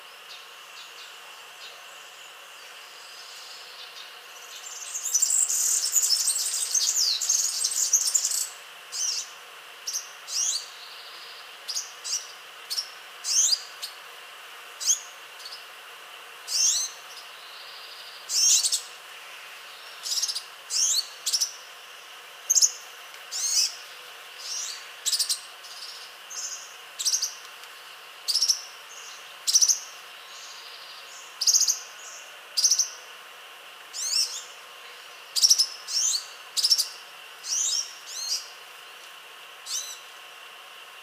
Csicsörke (Serinus serinus) hangja
A csicsörke (Serinus serinus) hangja leginkább egy folyamatos, gyorsan pergő, trillázó csicsergés, amely első hallásra akár egy apróbb fémes csilingelésre is emlékeztethet.
• Hangszín: világos, fémes, éles, de nem bántó.
• Ritmus: gyors és egyenletes, szünet nélkül.
• Magasság: a magasabb hangtartományban mozog, ami miatt távolabbról is hallható, még a városi zajban is.
Hívóhang: rövid, csipogó vagy csengettyűszerű „tíí” vagy „tszrr” hangok.